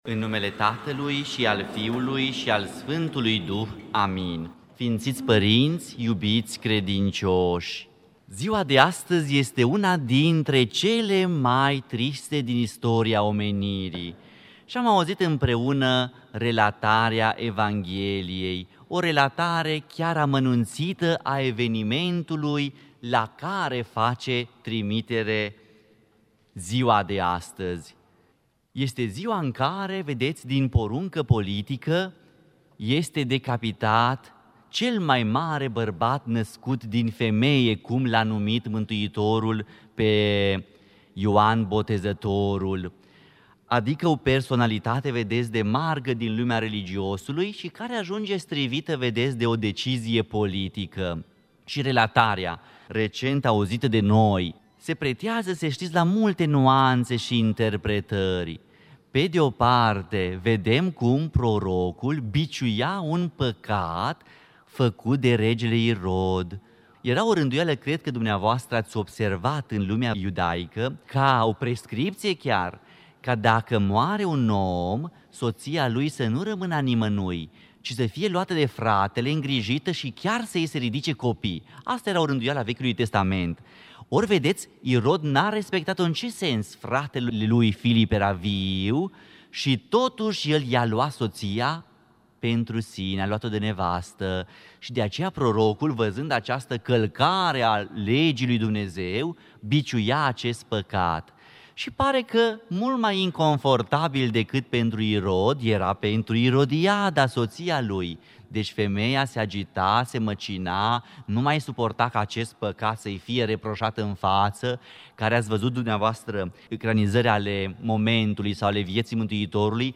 Predică la Tăierea capului Sf. Prooroc Ioan Botezătorul
Cuvinte de învățătură Predică la Tăierea capului Sf.